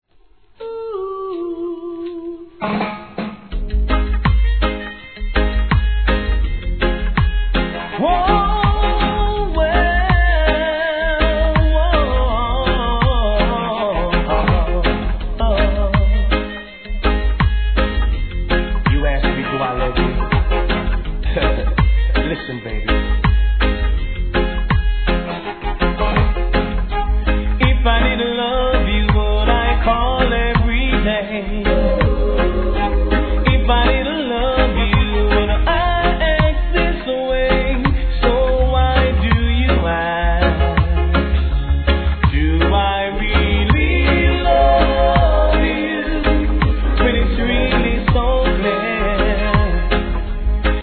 REGGAE
R&B HITカヴァー♪